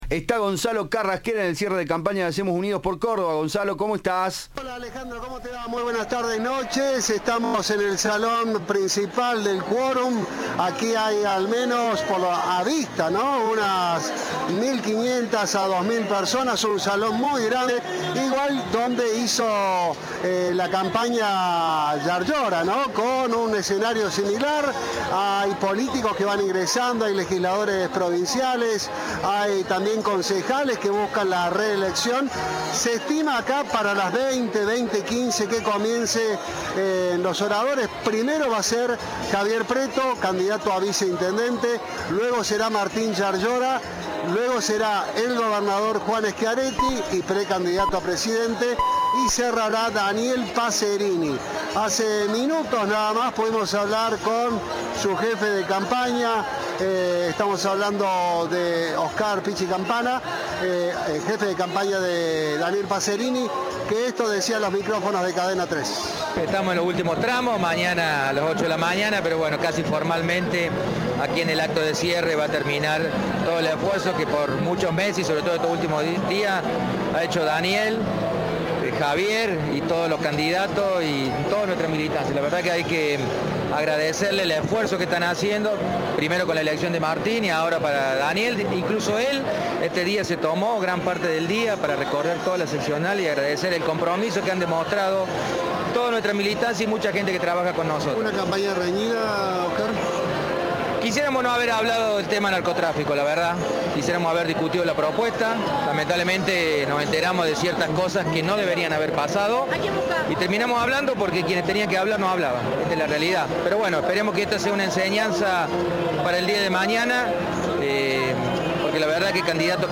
El candidato a intendente de la ciudad por el oficialismo fue apoyado en un acto en el Hotel Quorum por el precandidato presidencial Juan Schiaretti y el gobernador electo Martín Llaryora.
Daniel Passerini, candidato a intendente por el espacio oficialista Hacemos Unidos por Córdoba, cerró su campaña este jueves por la noche en el Hotel Quorum de la ciudad de Córdoba con un mensaje enfocado en las obras y lo que su espacio "hizo por la ciudad".